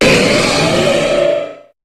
Cri de Méga-Cizayox dans Pokémon HOME.
Cri_0212_Méga_HOME.ogg